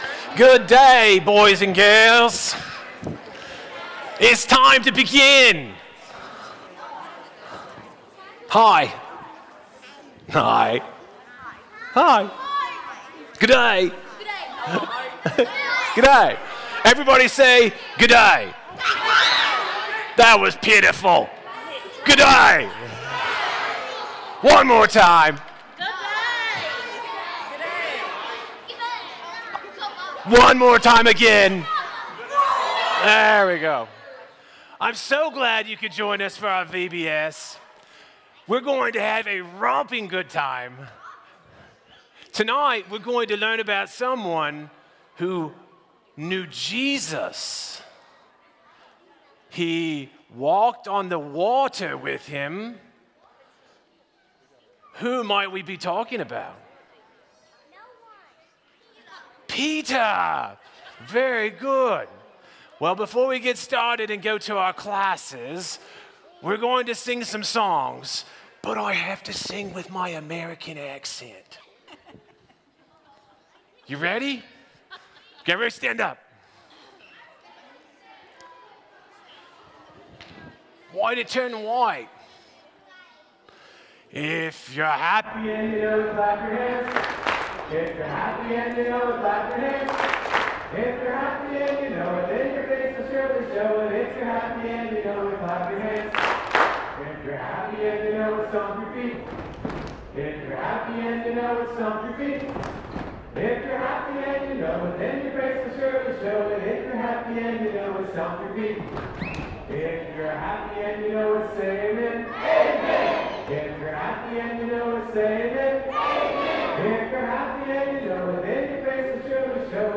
Matthew 14:28-29, English Standard Version Series: Sunday PM Service